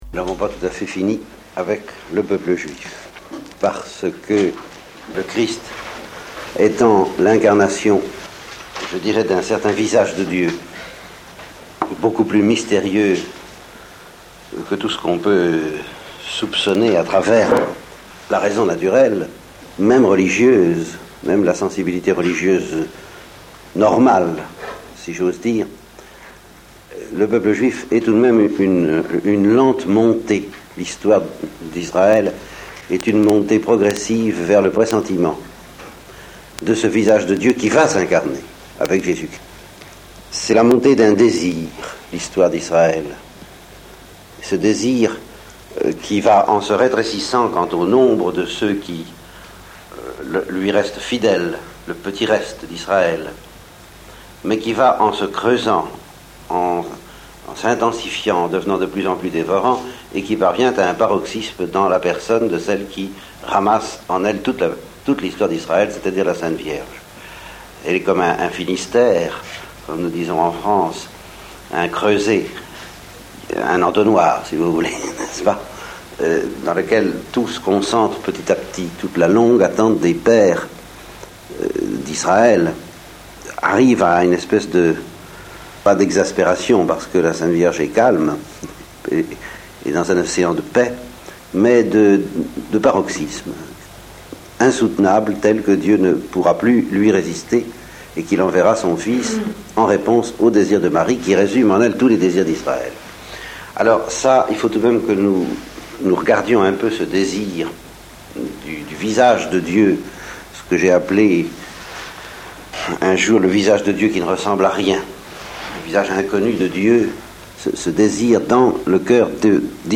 Enseignement